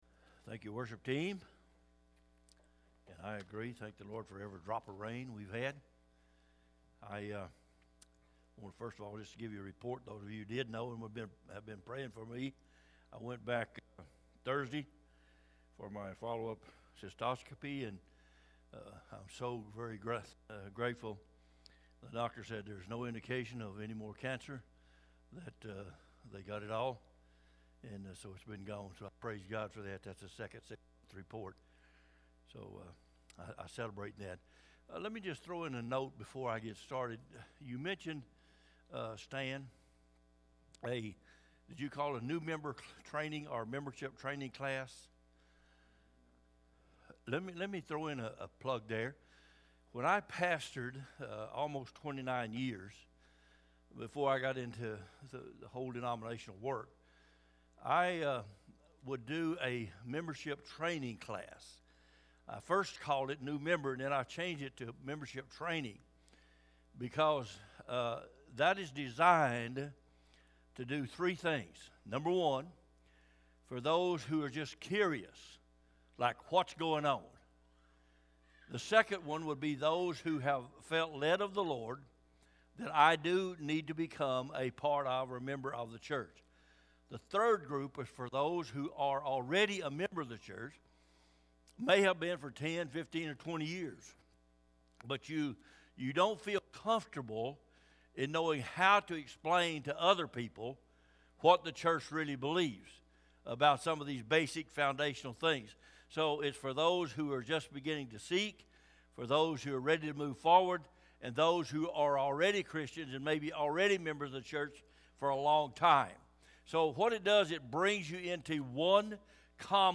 Aug-22-sermon-audio.mp3